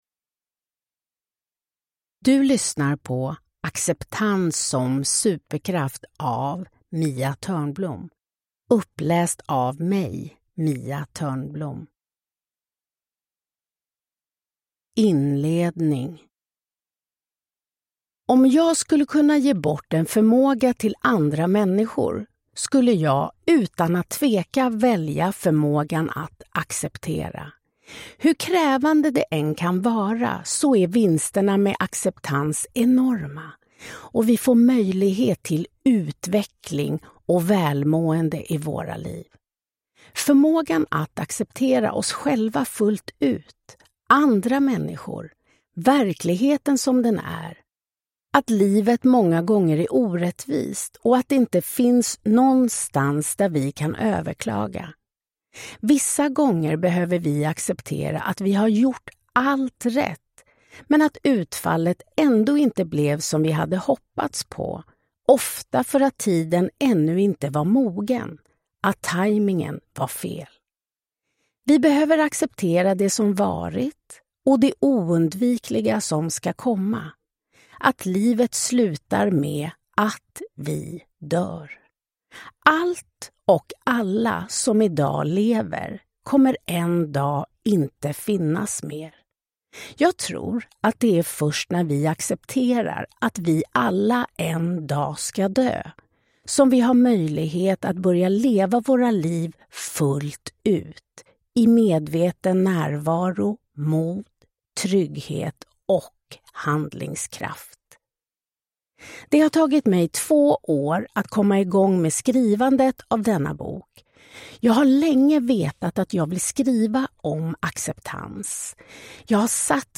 Uppläsare: Mia Törnblom
Ljudbok